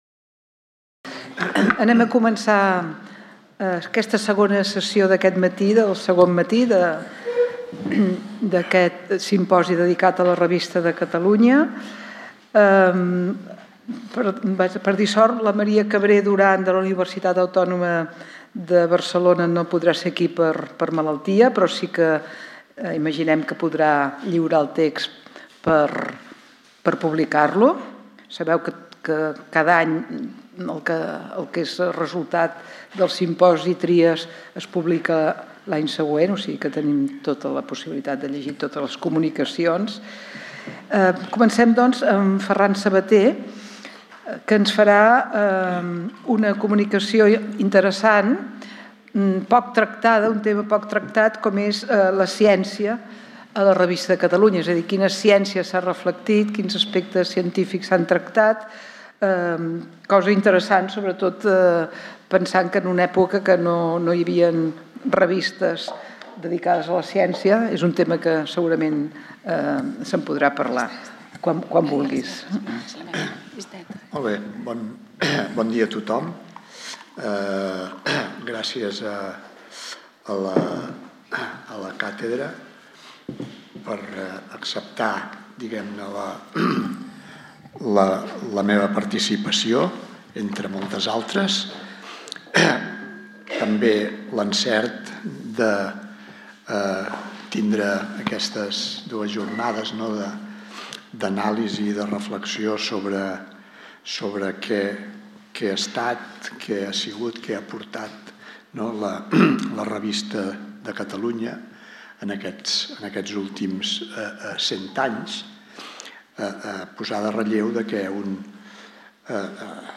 en el marc del Simposi Trias 2024 sobre el centenari de la Revista de Catalunya